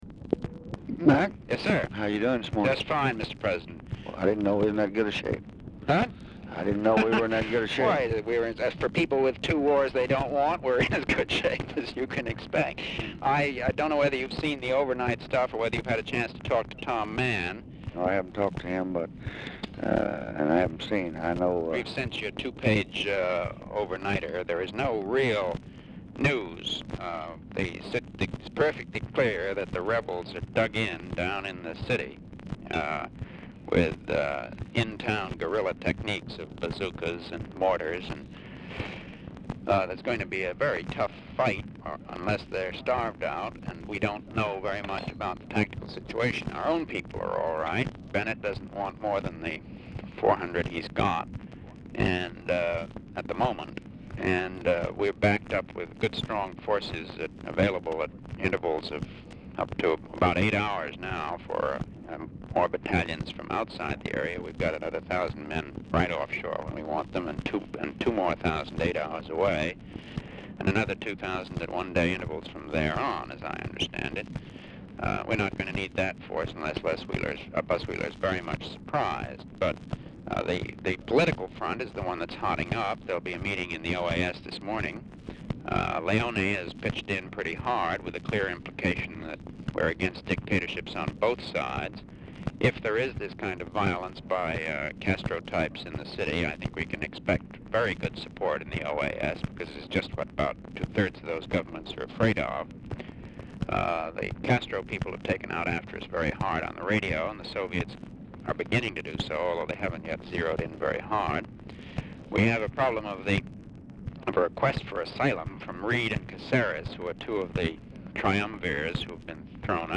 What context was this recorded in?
Mansion, White House, Washington, DC Dictation belt